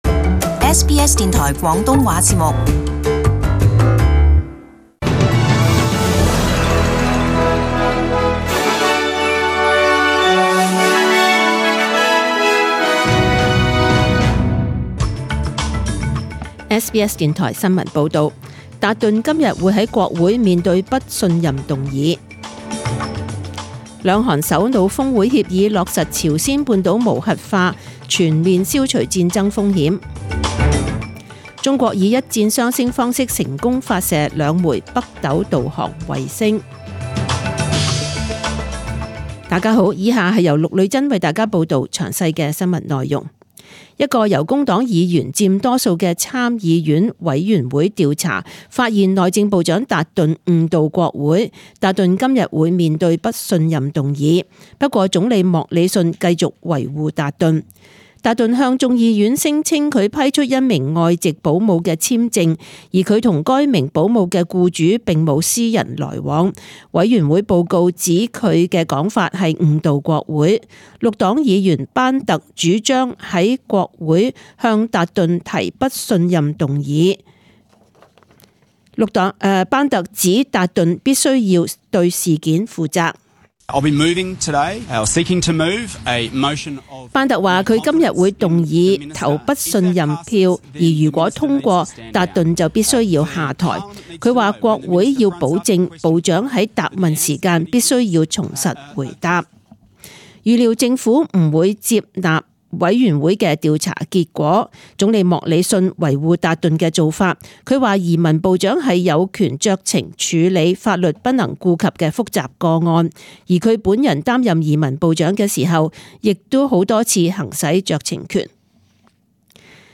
SBS中文新闻 （九月二十日）